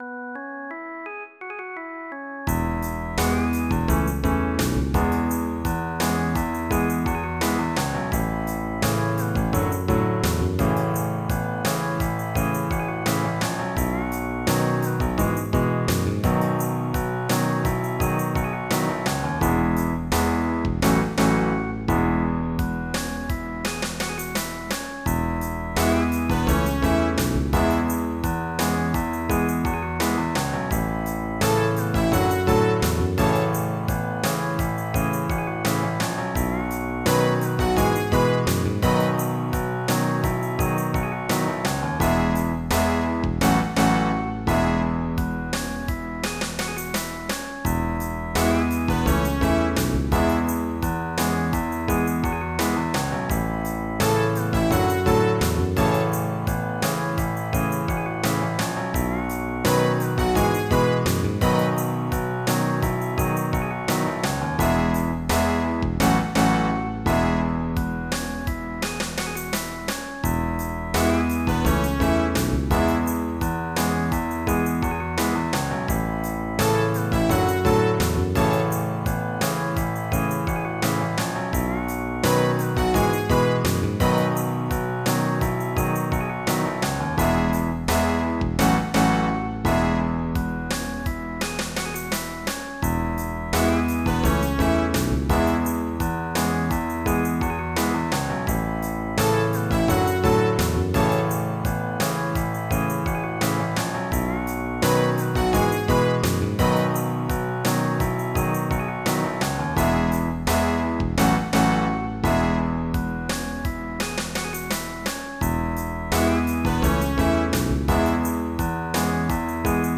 MIDI Music File
Type General MIDI (type 1)